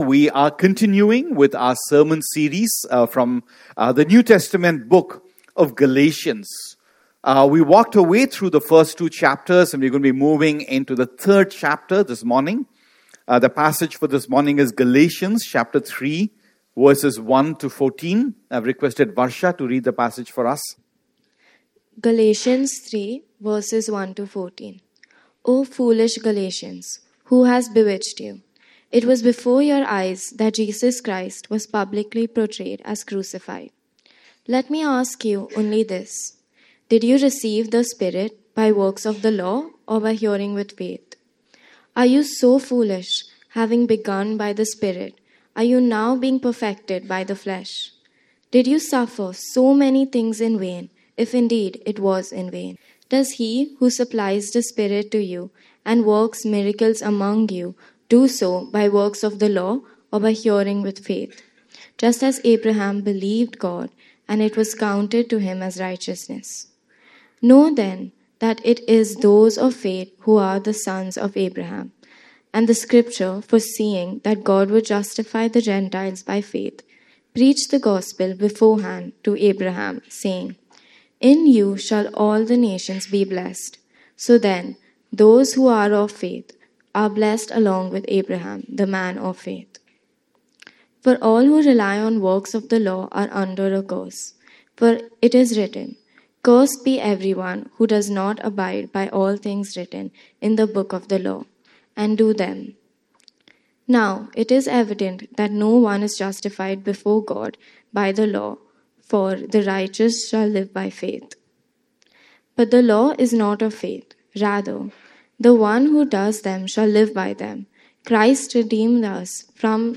Sermons // Gospel-Centered // Life-Changing // Motivating //